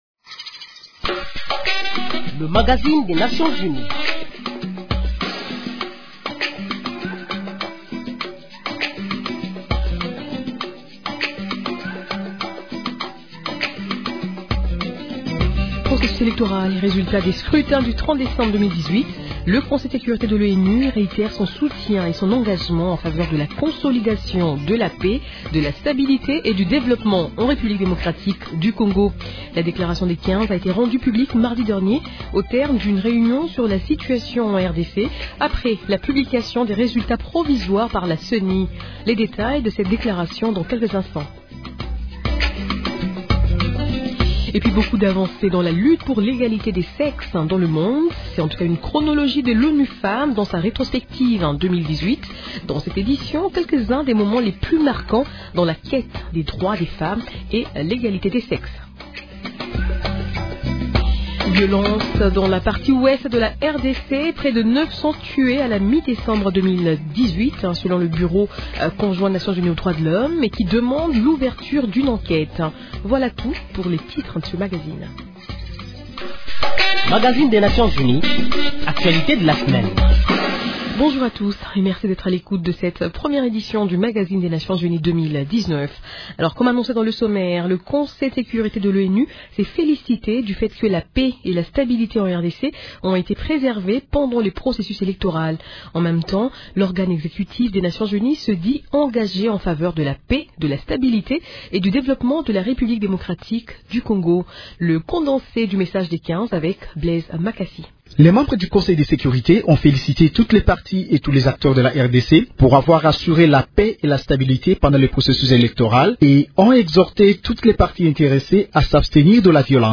Brèves *En plus bref, commençons par l’Est de la RDC. Il s’est observé de lundi à mercredi de la semaine, des vols d’entrainement de routine des hélicoptères de la Monusco dans le ciel de Beni, au Nord-Kivu.